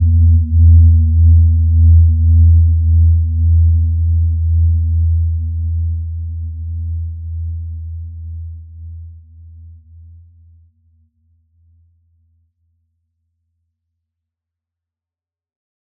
Gentle-Metallic-3-E2-p.wav